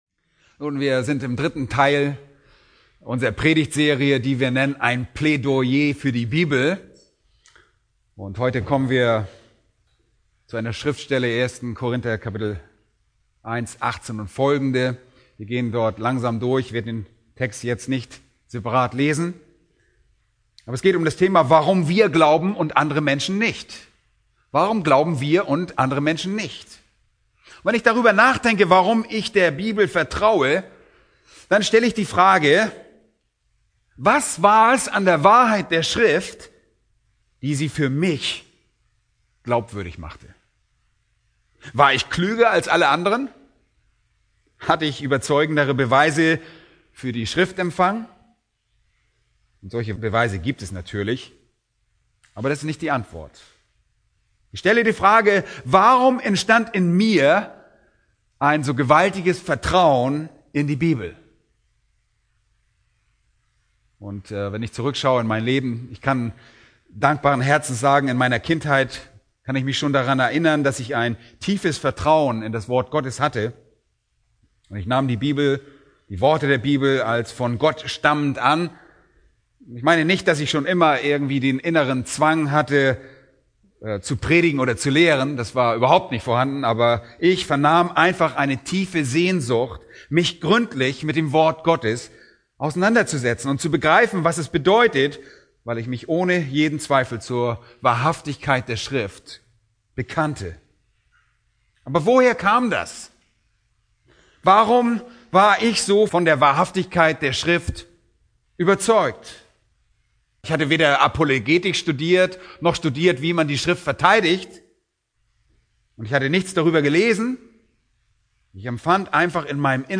A predigt from the serie "Systematische Theologie."